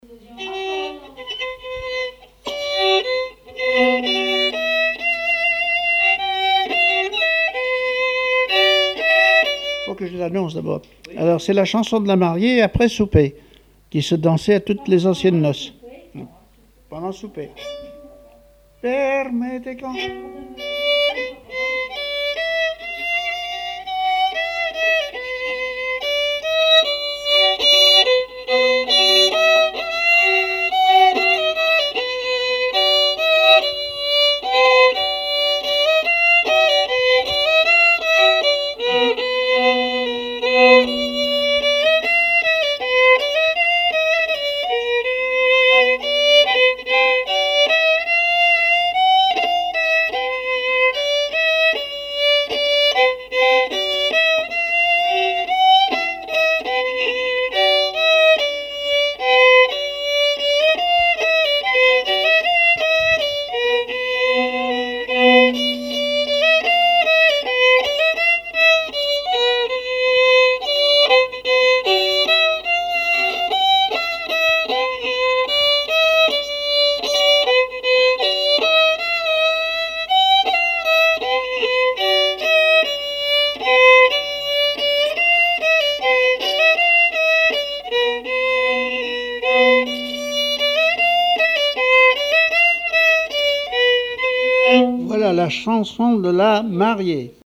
Genre strophique
répertoire musical au violon
Pièce musicale inédite